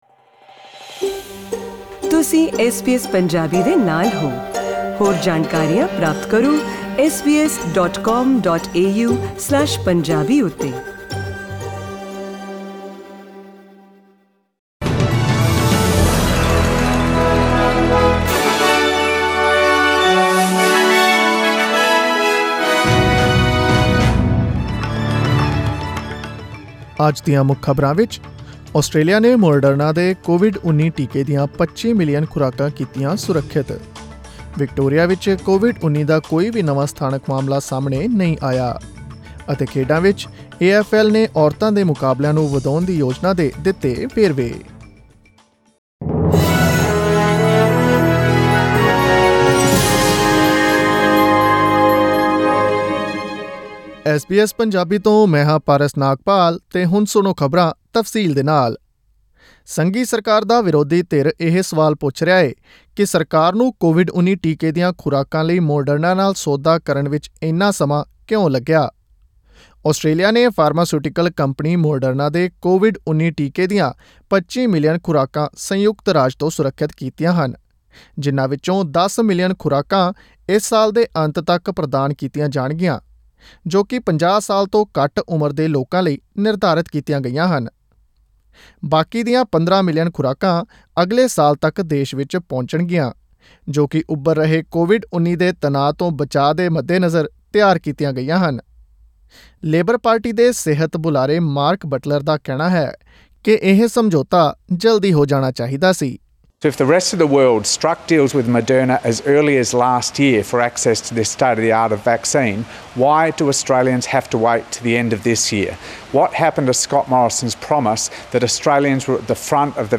Click on the audio icon in the picture above to listen to the news bulletin in Punjabi.